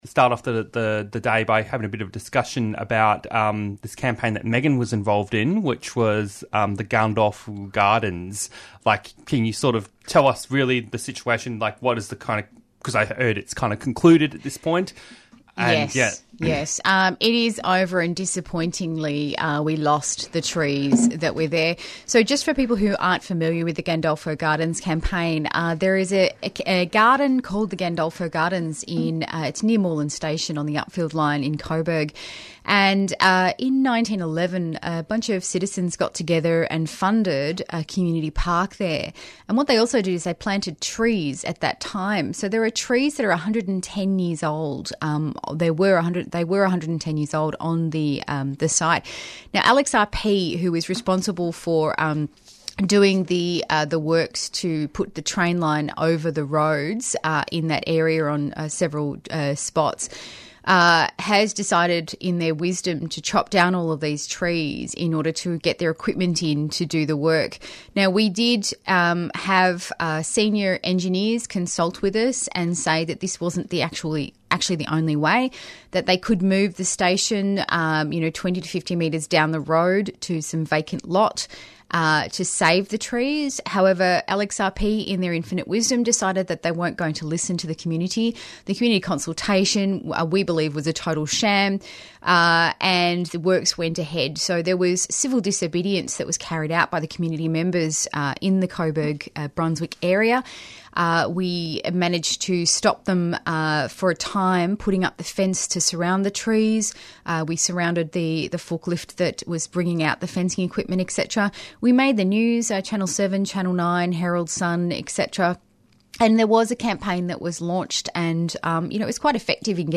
Interviews and Discussion